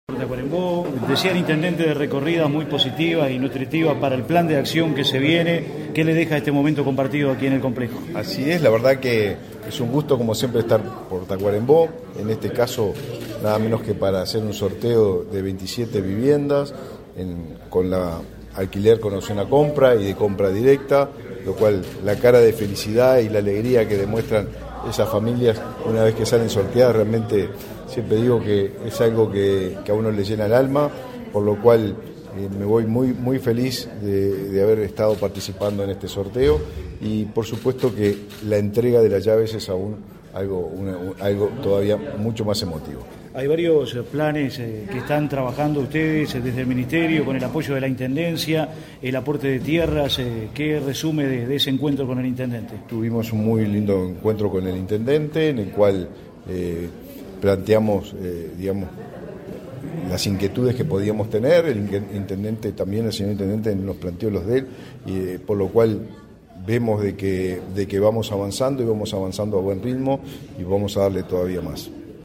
Declaraciones a la prensa del ministro de Vivienda, Raúl Lozano
Declaraciones a la prensa del ministro de Vivienda, Raúl Lozano 01/09/2023 Compartir Facebook X Copiar enlace WhatsApp LinkedIn Tras participar en el sorteo de viviendas en un edificio en Tacuarembó, este 1.° de setiembre, el titular del Ministerio de Vivienda y Ordenamiento Territorial (MVOT), Raúl Lozano, realizó declaraciones a la prensa.
Lozano prensa.mp3